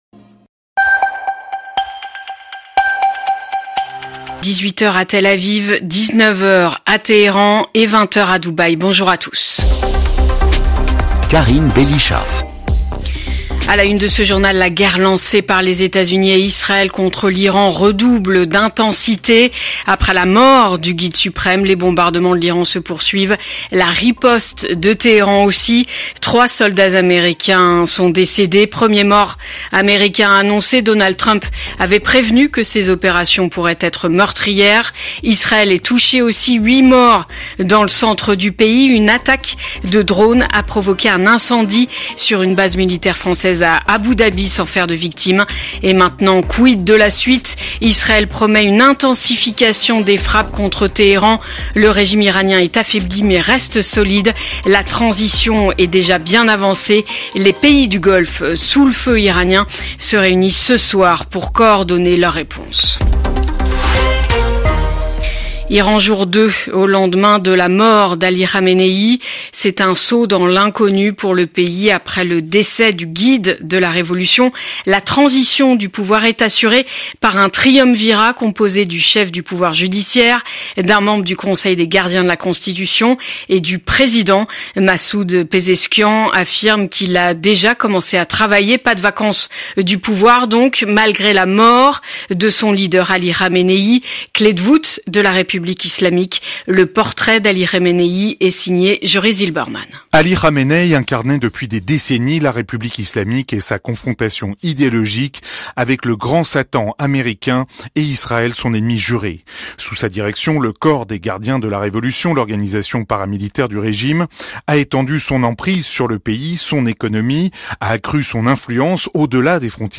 Flash infos 10/04/2026